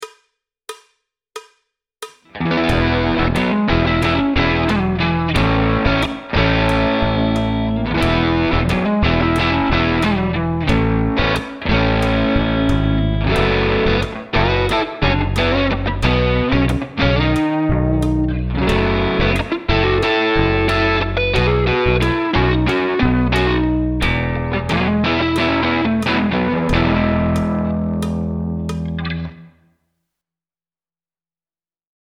Rhythm Guitar 1
JIMI HENDRIX style
Jimi Hendrix style click senza batteria.mp3